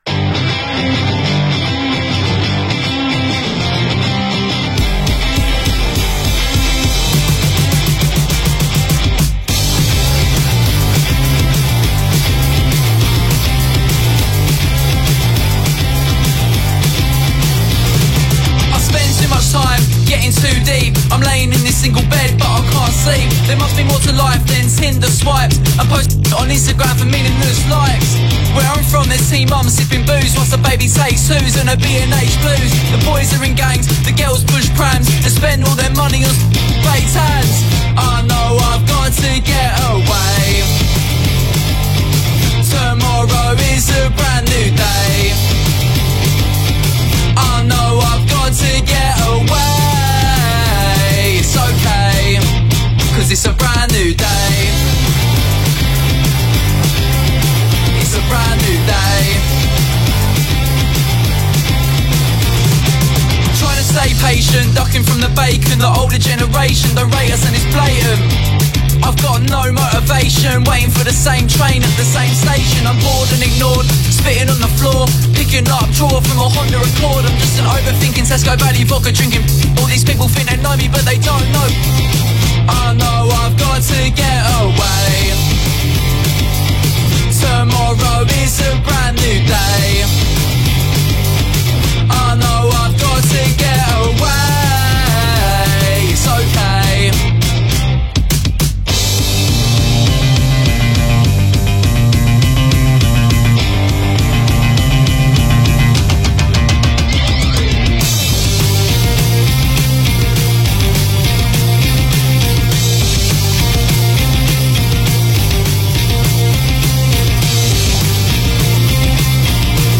Primarily an acoustic session
three musicians
a unique angsty blend of indie, rap and punk
rowdy curt delivery oozes swag and rebellion